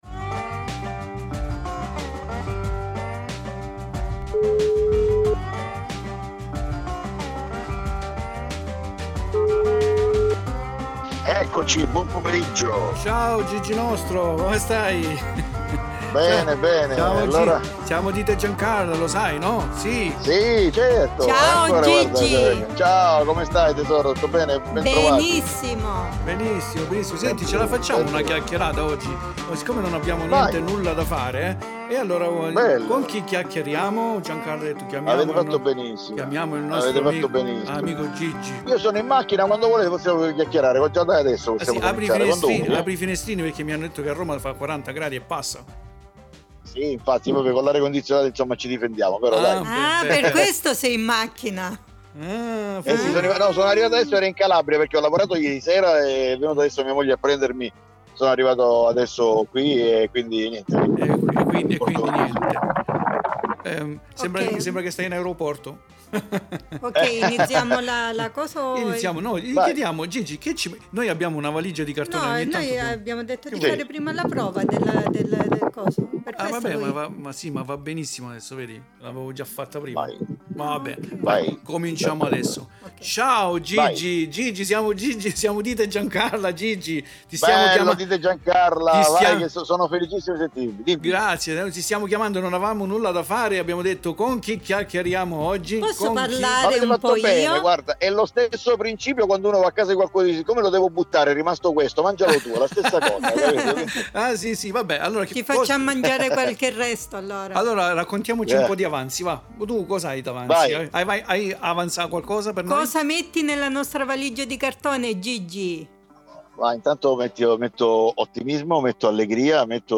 Interview GIGI MISEFERI
Qui l'Intervista integrale come promesso nel programma del primo agosto .